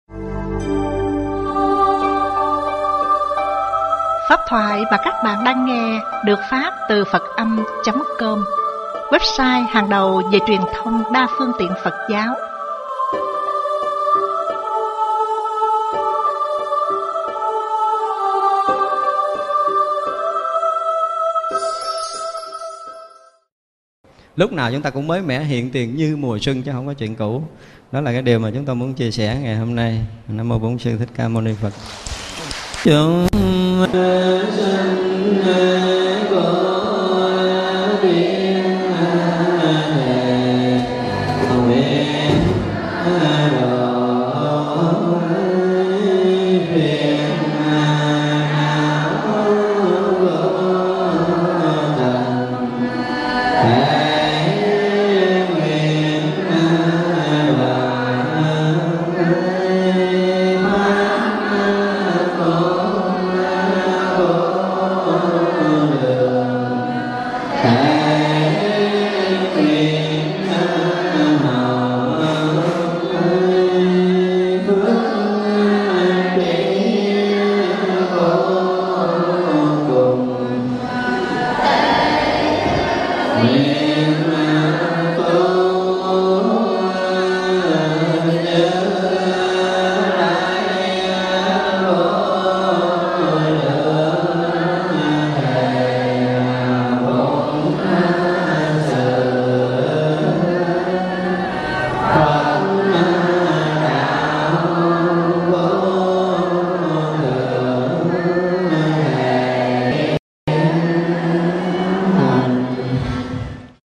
Đây là bài thuyết giảng mang tính chuyên môn cao, rất hữu ích cho những người tu chuyên sâu và những người luôn mong cầu học Đạo để đi đến Giác Ngộ Giải Thoát và Tự Do Sinh ......